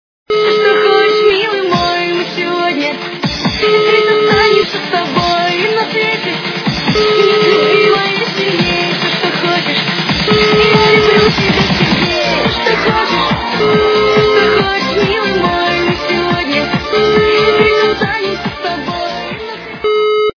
русская эстрада
качество понижено и присутствуют гудки